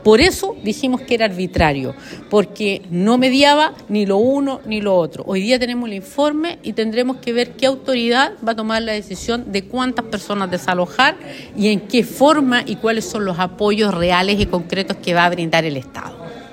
La diputada por el distrito 21 Joanna Pérez indicó que la tardanza en la entrega de los informes ha sido determinante en la toma de decisiones y acusó que la entrega de la información no ha sido la óptima.